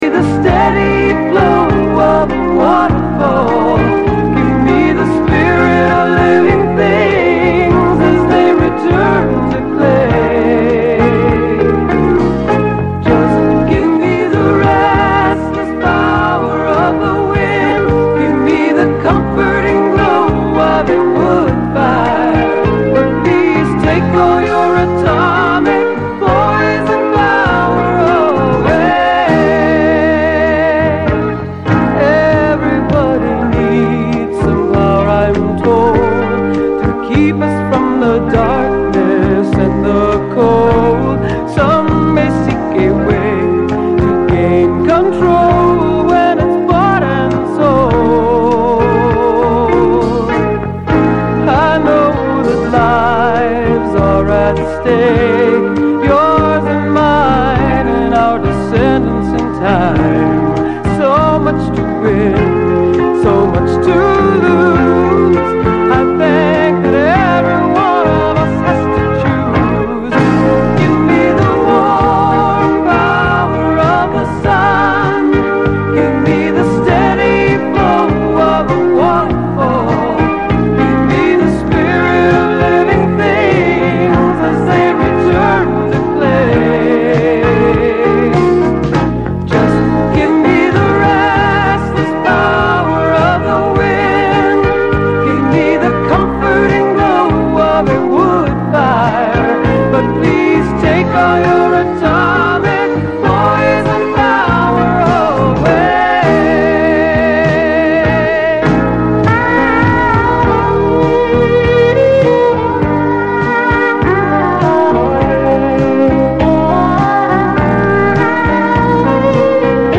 Anti-nuke songs.